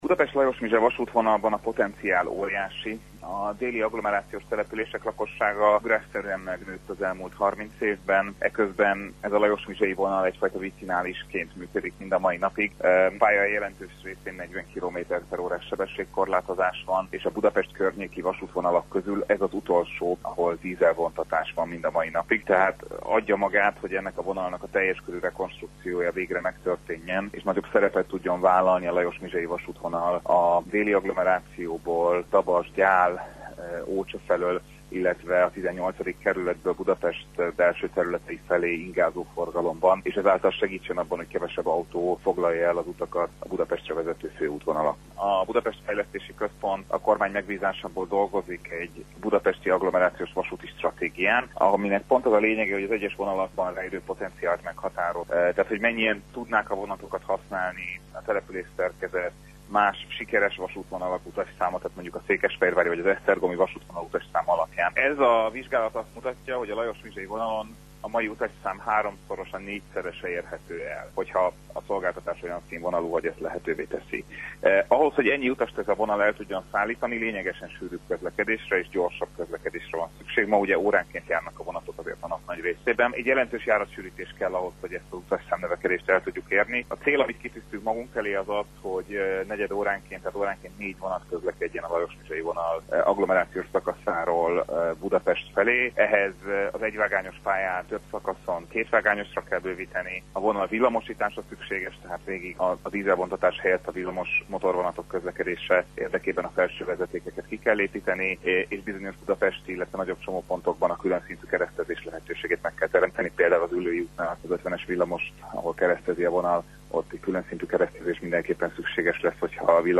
Nagyobb szerepet szánnának a Budapest – Lajosmizse vasútvonalnak, a cél az, hogy a jövőben háromszor – négyszer annyian utazzanak ezen a szakaszon, mint jelenleg, illetve a járatok sűrítése is szerepel a tervekben, erről Vitézy Dávid a Budapest Fejlesztési Központ vezérigazgatója beszélt rádiónknak.